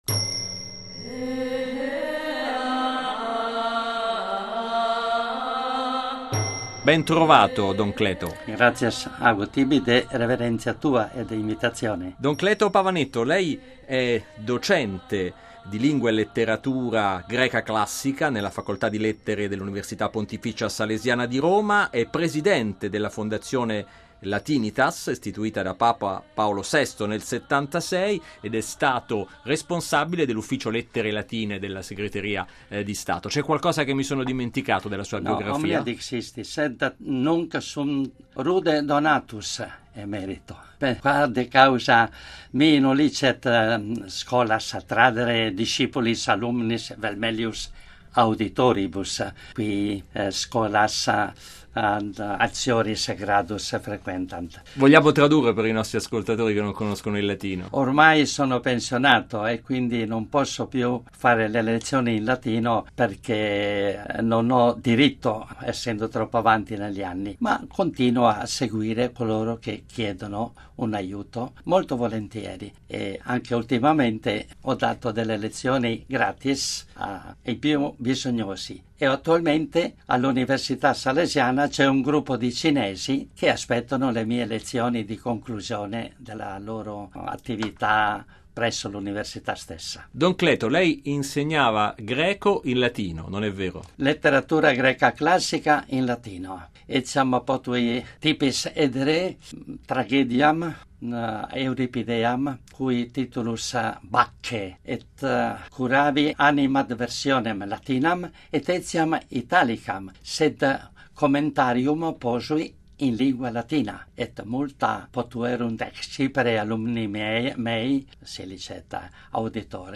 Mi ero dimenticato di aggiungere a fondo pagina l’intervista completa e ve la inoltro qui di seguito, meglio tardi che mai…